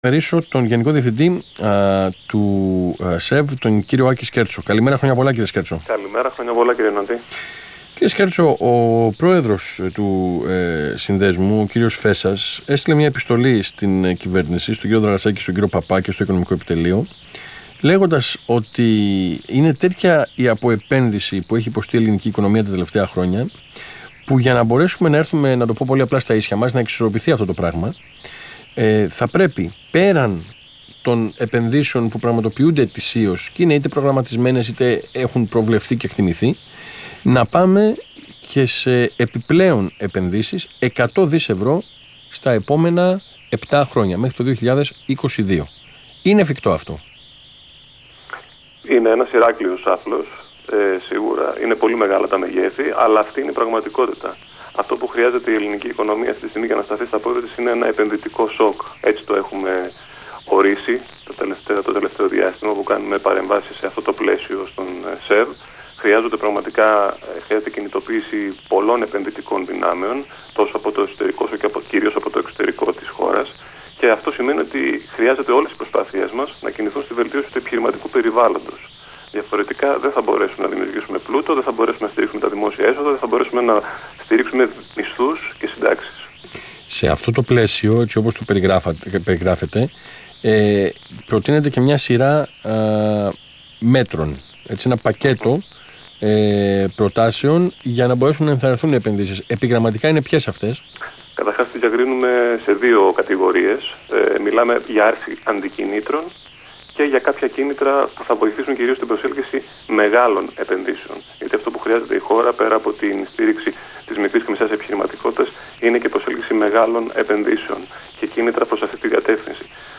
Συνέντευξη του Γενικού Διευθυντή του ΣΕΒ, κ. Άκη Σκέρτσου στον Ρ/Σ Αθήνα 9.84, 30/12/15